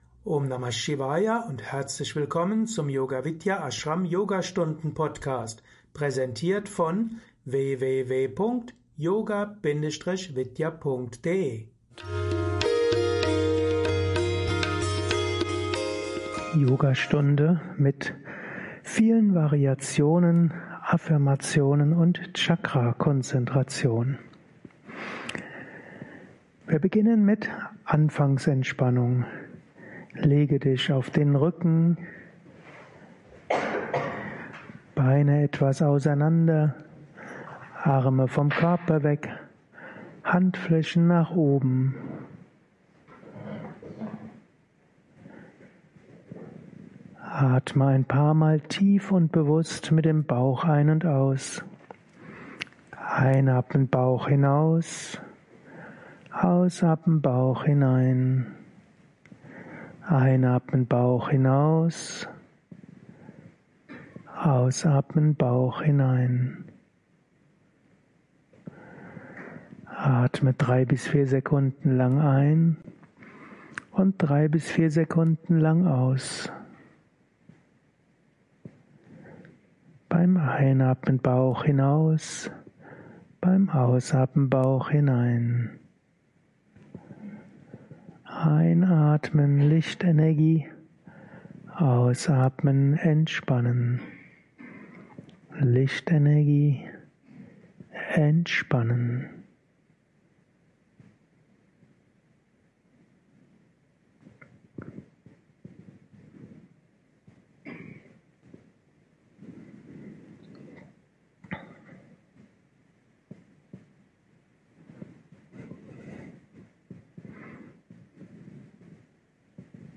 Jubiläumsyogastunde